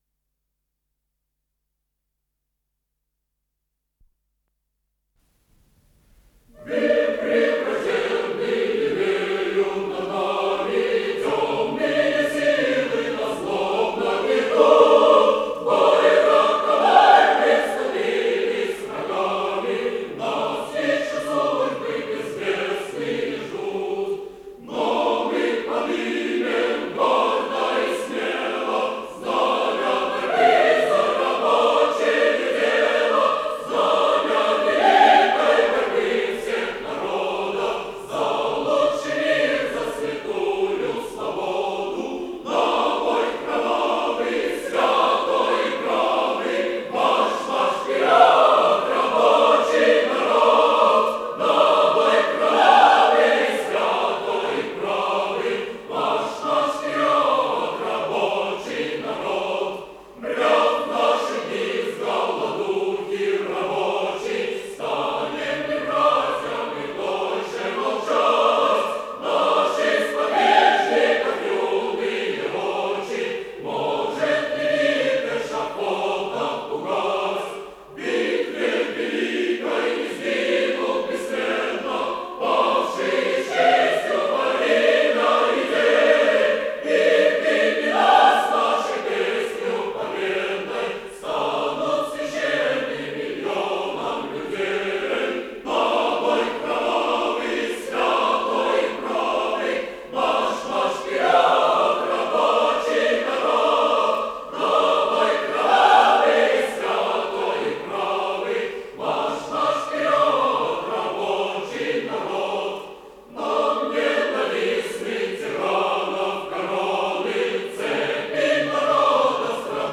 с профессиональной магнитной ленты
КомпозиторыРеволюционная песня
ИсполнителиАнсамбль Студии Советской Песни ЦТ и Р
ВариантДубль моно